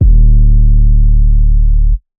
808 5 (D).wav